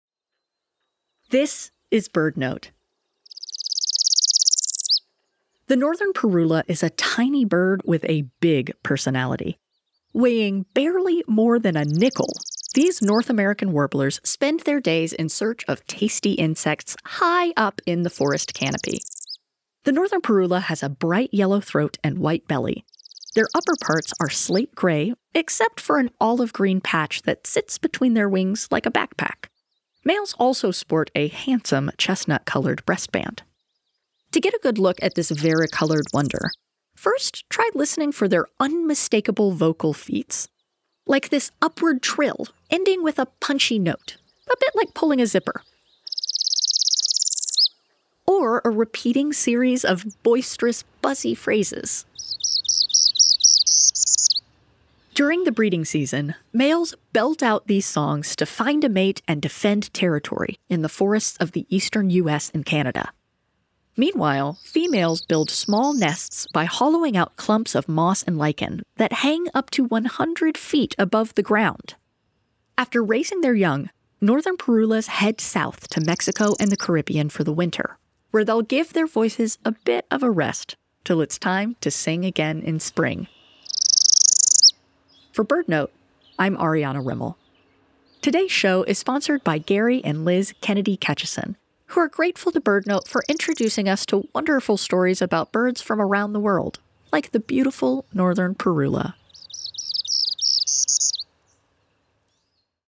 Northern Parula Sings From on High (BirdNote: 06/06/25) – Chirp Nature Center
The Northern Parula is a tiny bird with a big personality. These colorful warblers spend their days in search of tasty insects high up in the forest canopy of woodlands in North America. To get a good look, first try listening for their unmistakable vocal feats!